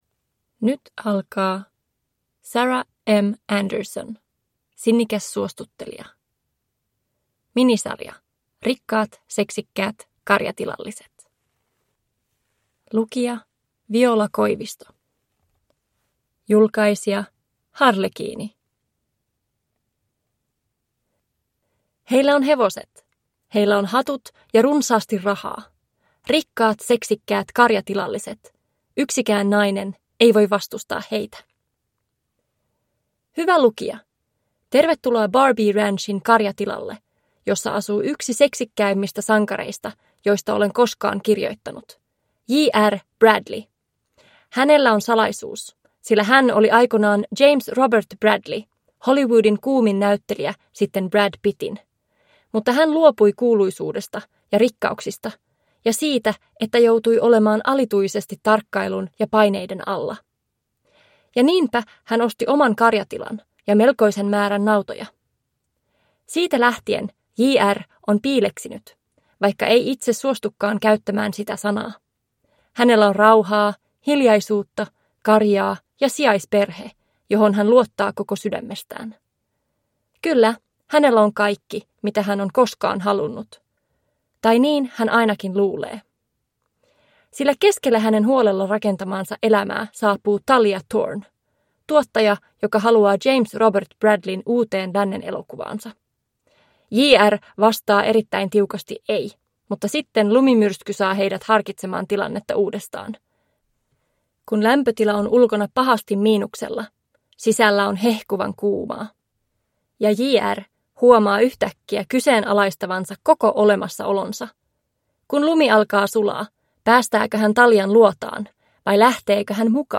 Sinnikäs suostuttelija – Ljudbok – Laddas ner